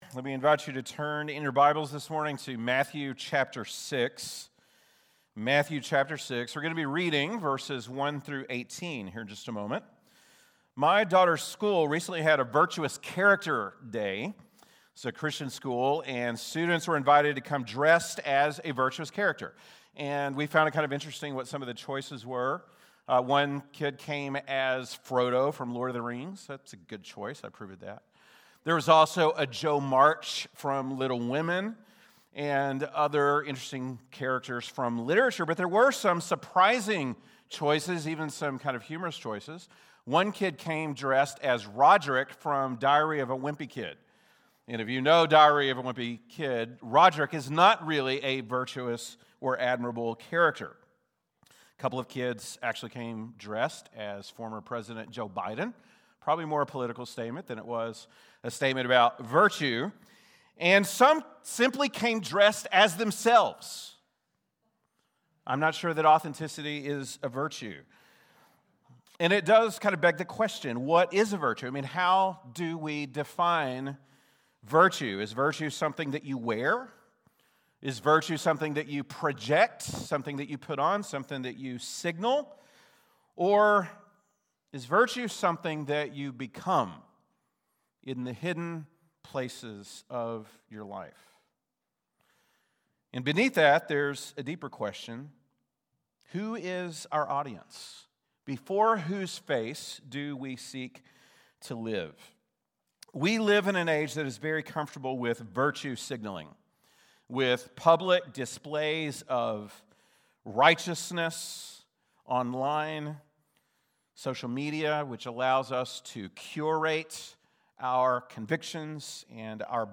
February 22, 2026 (Sunday Morning)